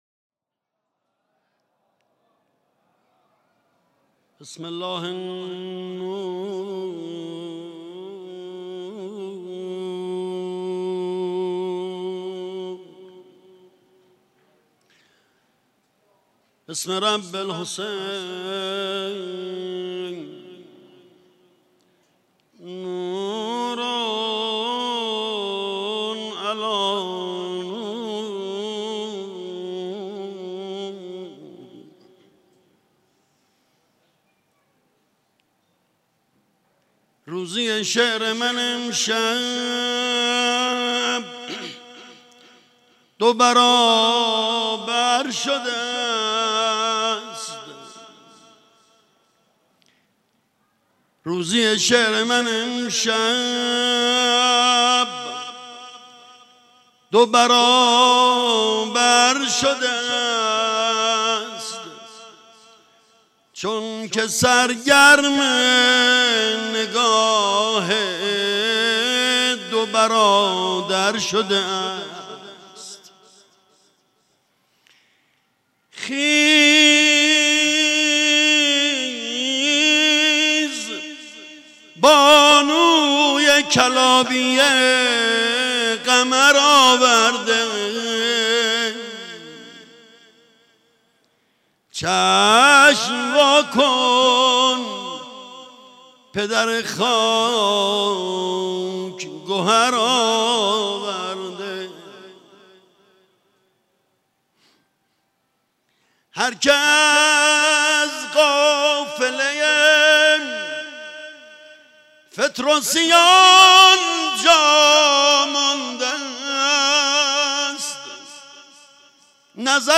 مراسم جشن میلاد حضرت عباس(ع) با مداحی
در مسجد حضرت امیر(ع) برگزار شد.
مداحی